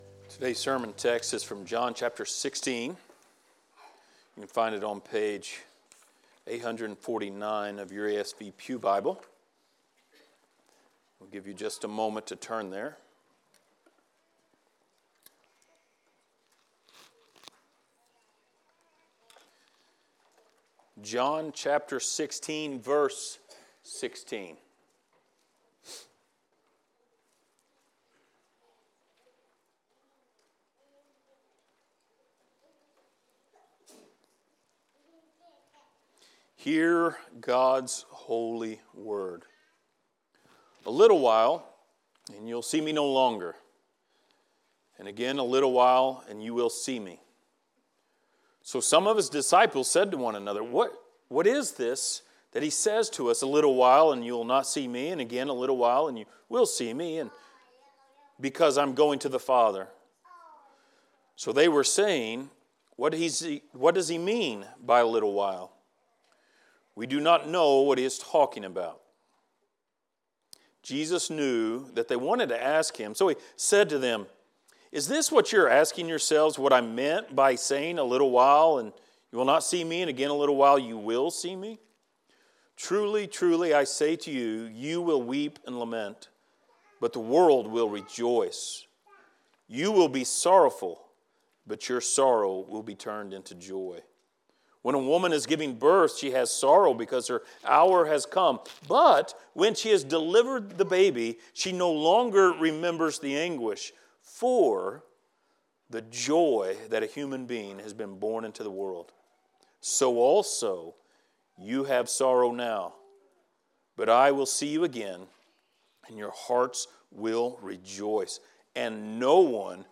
Passage: John 16:16-24 Service Type: Sunday Morning